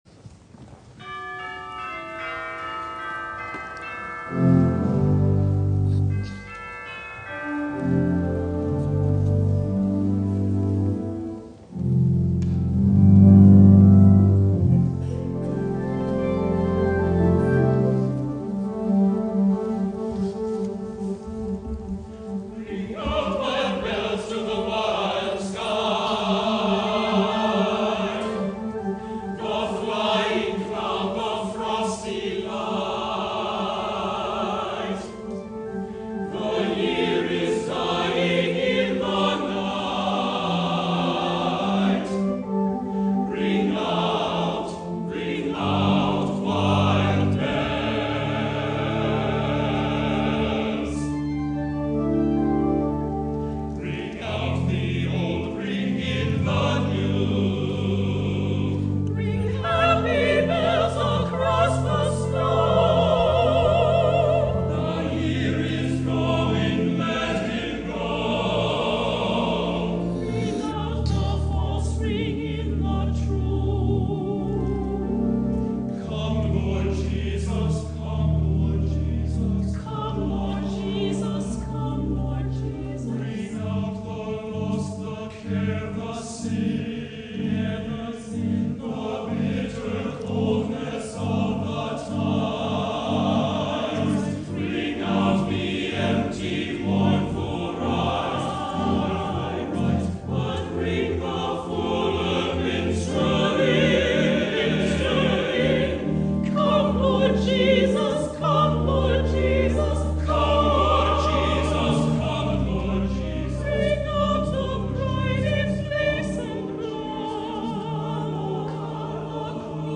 for SATB Chorus and Organ (2006)
SATB and organ